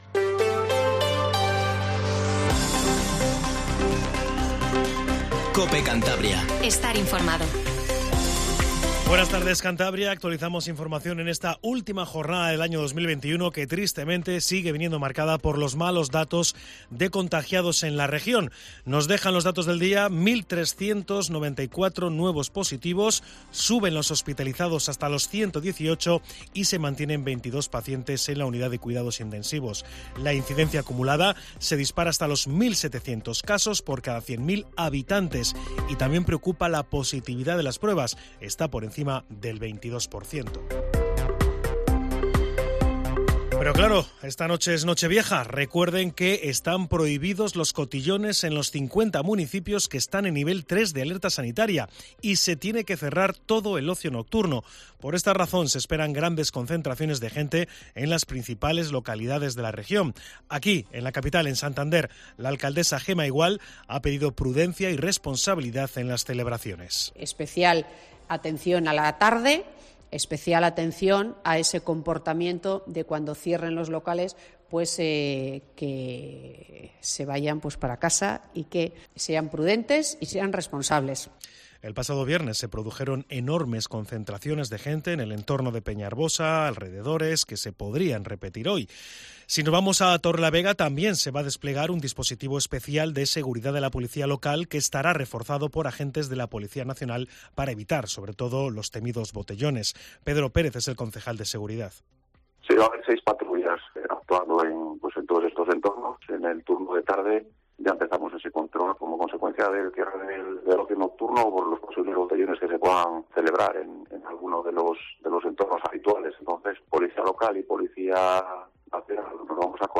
Informativo Mediodía COPE CANTABRIA